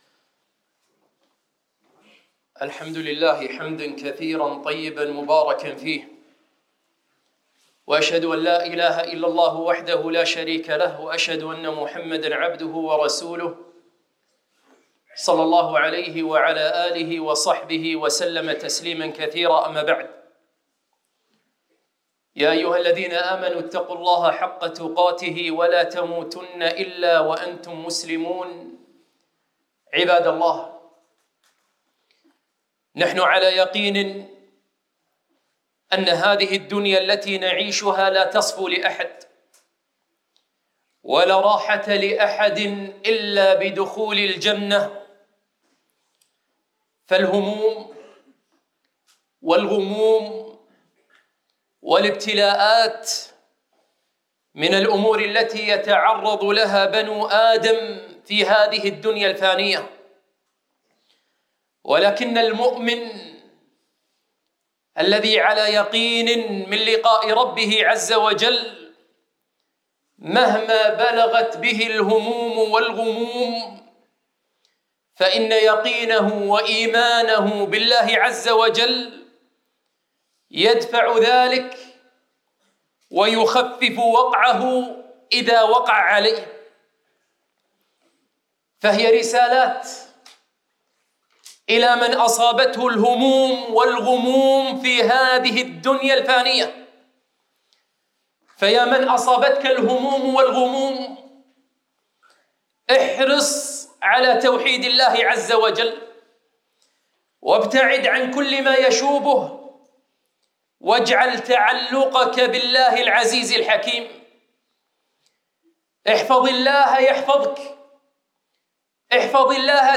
خطبة - يا من أصابته الهموم والغموم